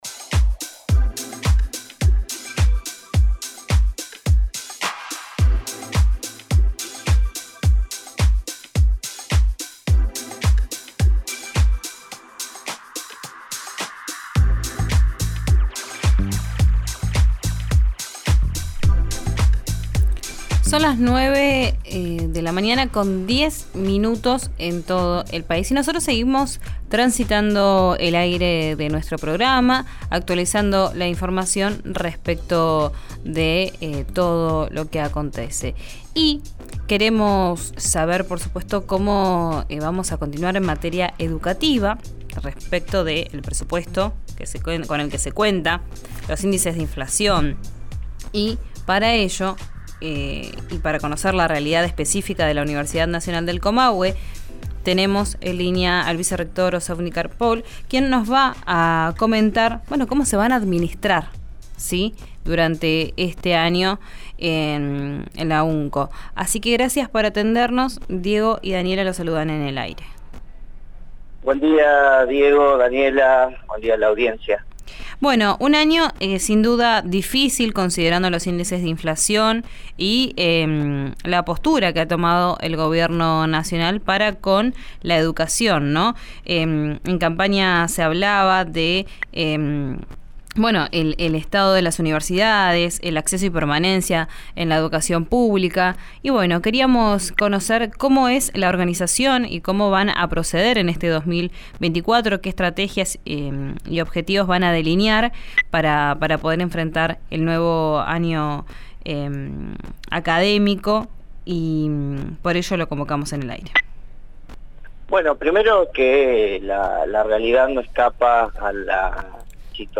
En diálogo con RÍO NEGRO RADIO señaló que los fondos solo alcanzarían para cubrir el funcionamieto hasta junio.